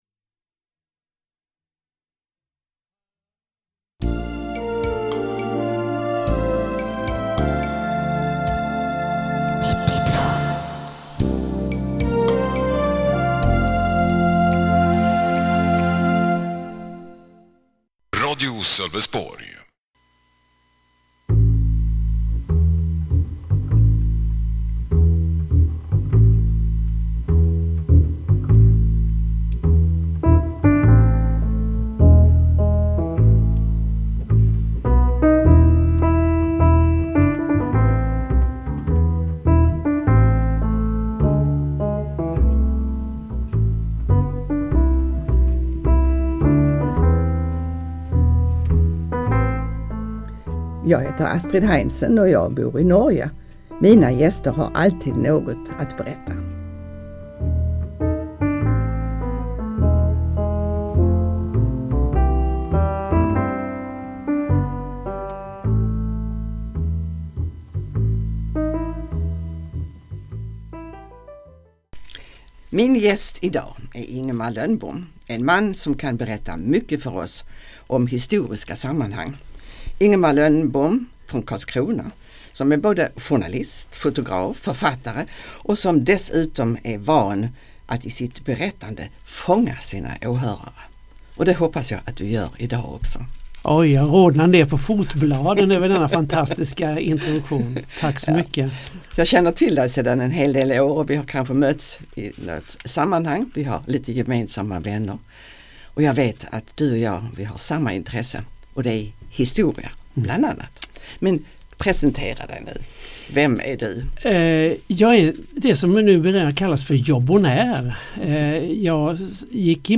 Programmet sändes i mitten av februari i Sölvesborgs närradio 92,0.